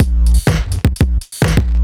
OTG_Kit8_Wonk_130a.wav